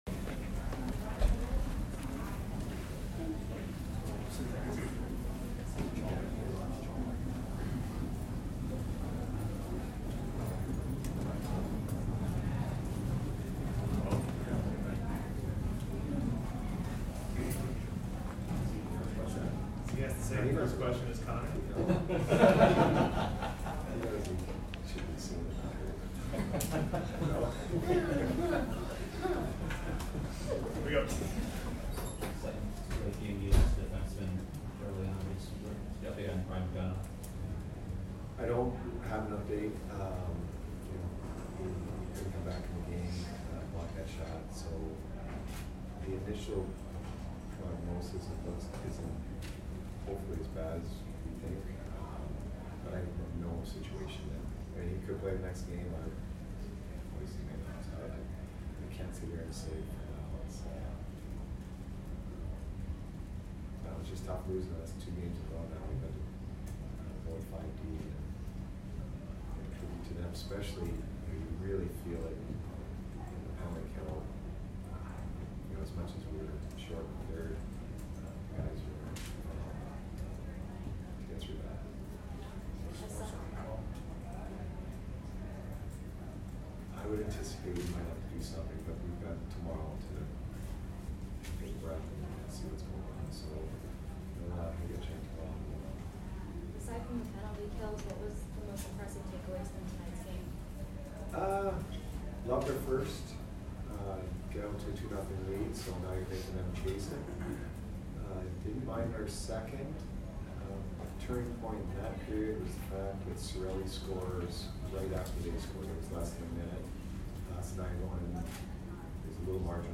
Cooper post-game 2/6